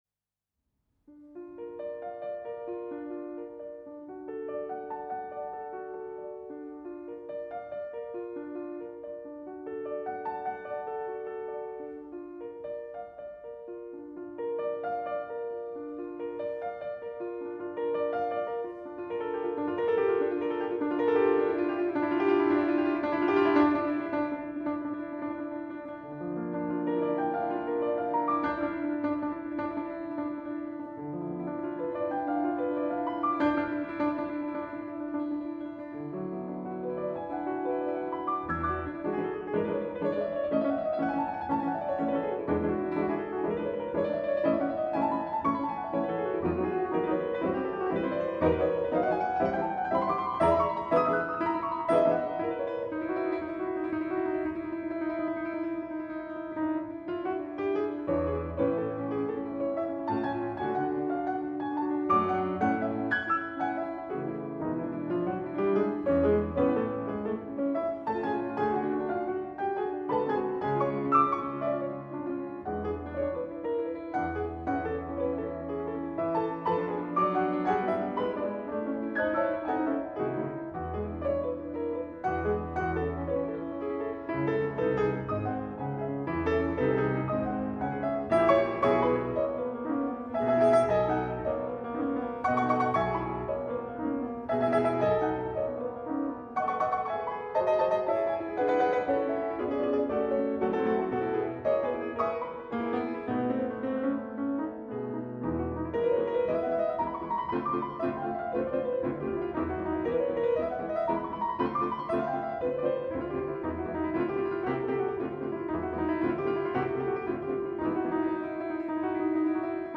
arr. for 2 pianos by composer